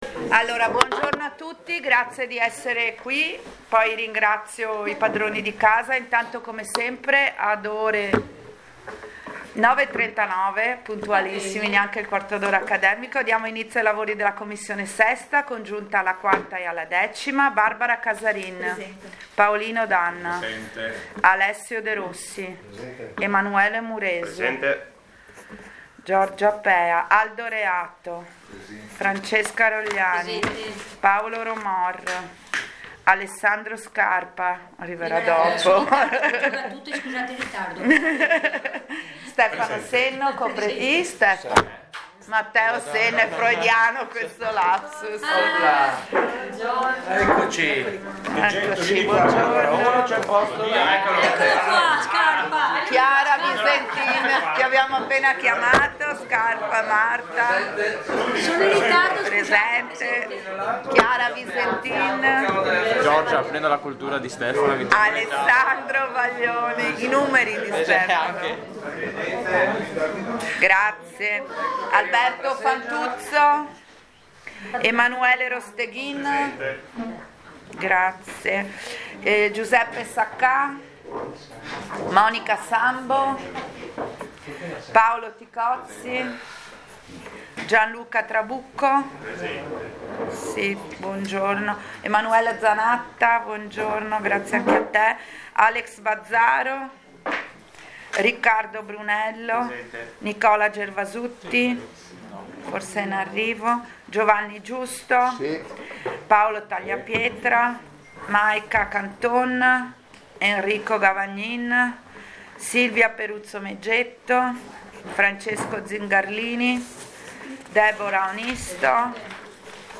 Convocazione e atti della seduta congiunta alla IV Commissione e alla X Commissione